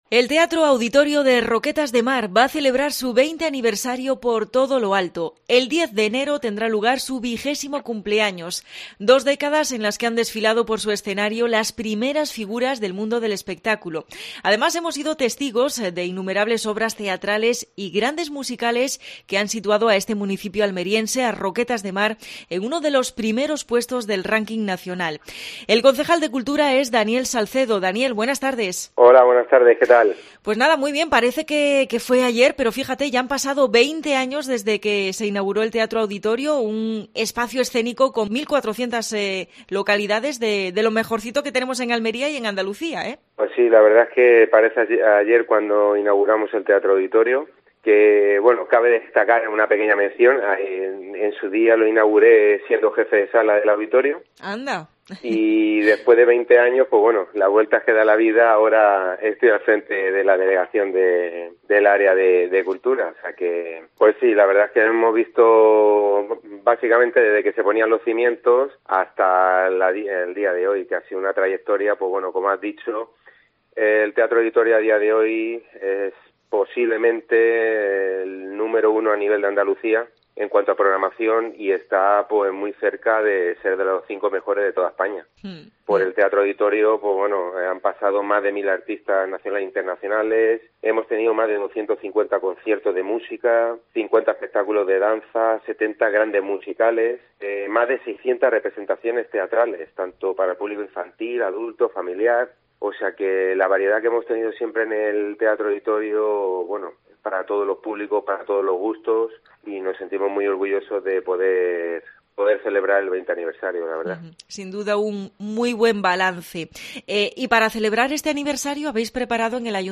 AUDIO: Entrevista al concejal de Cultura de Roquetas de Mar, Daniel Salcedo.